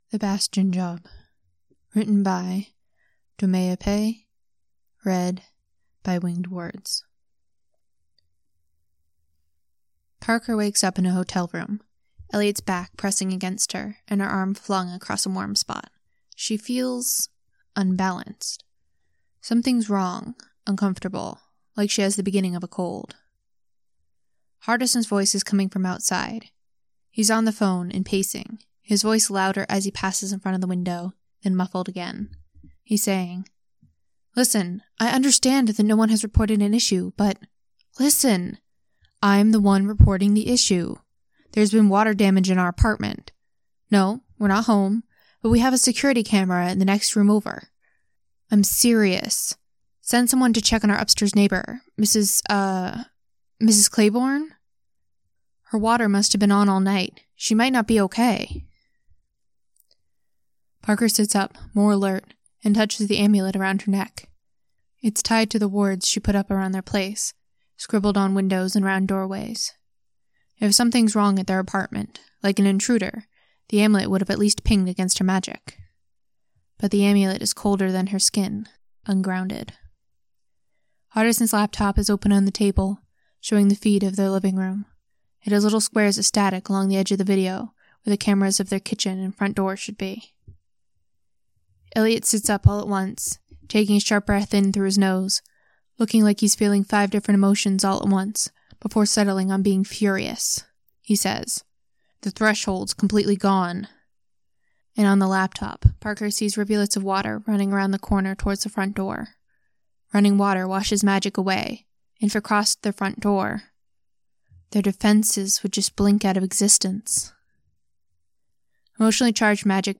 Download or Stream : MP3 (22.1 MB) ||| M4B (18.3 MB) Length : 00:42:03 Stream :